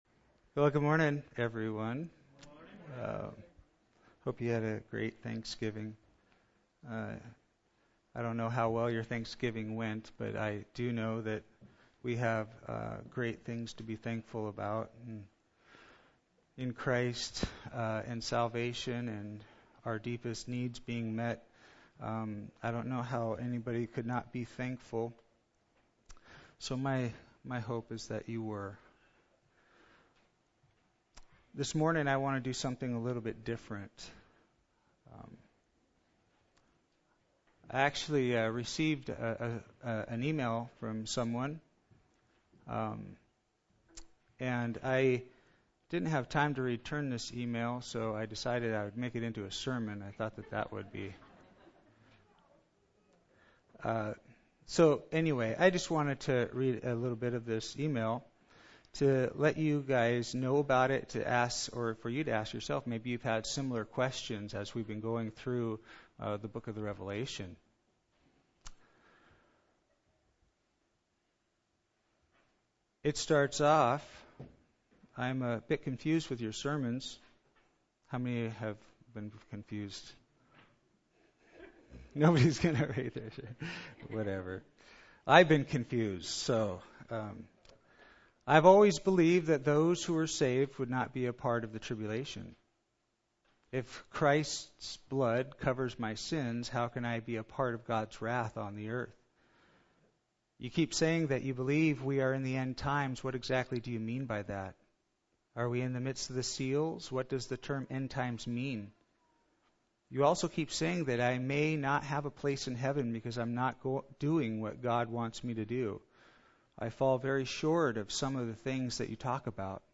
Thank you for your time and consideration in answering the questions of those in the congregation.